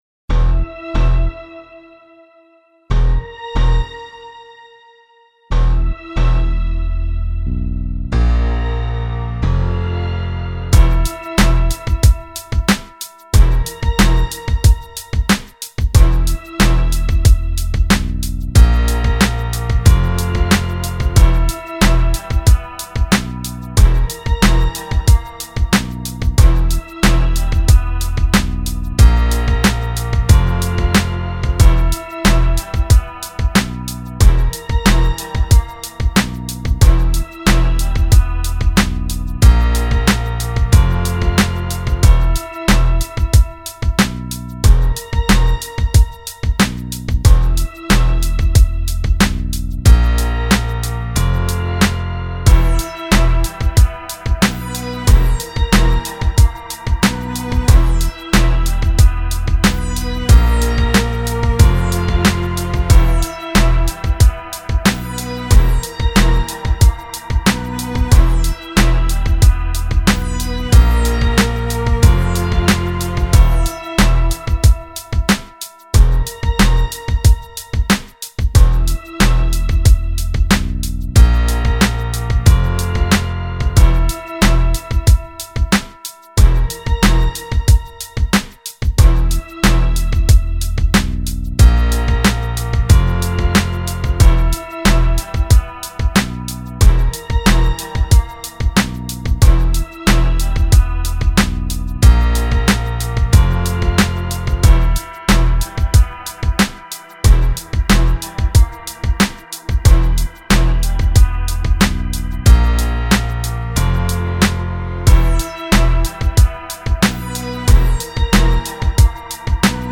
92 BPM.